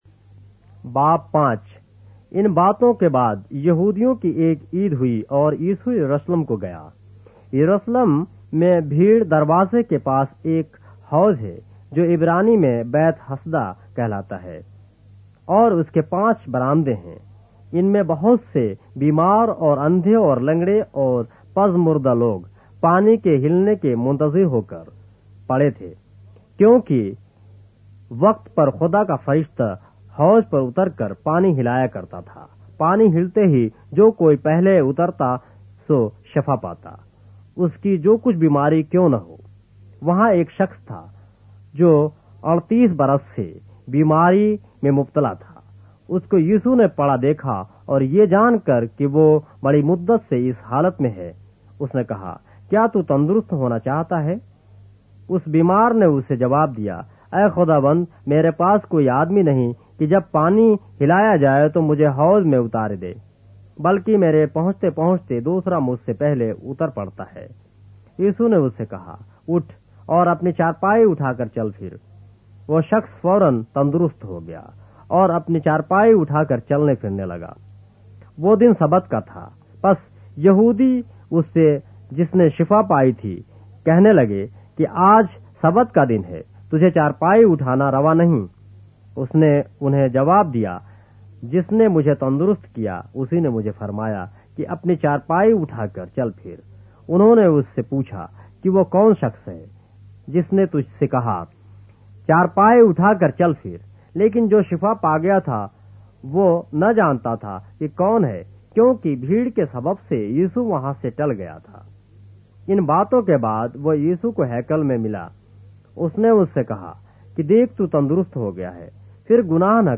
اردو بائبل کے باب - آڈیو روایت کے ساتھ - John, chapter 5 of the Holy Bible in Urdu